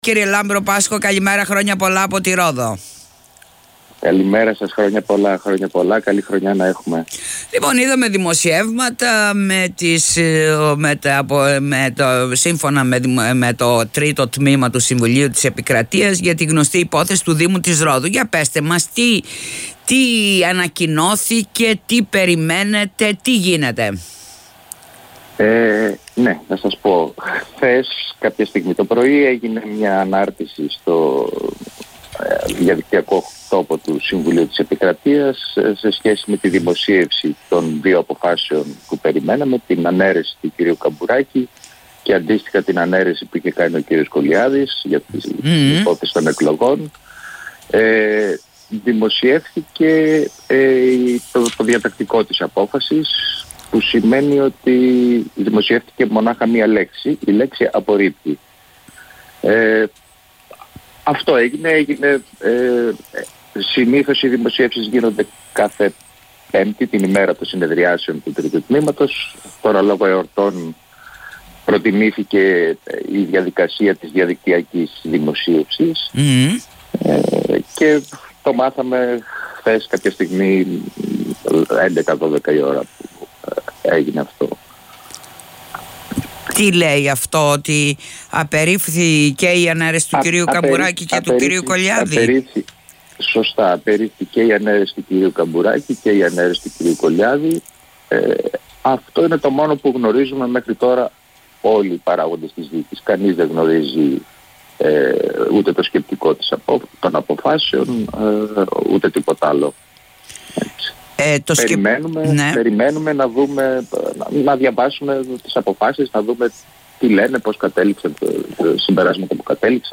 δήλωσε σήμερα στον topfm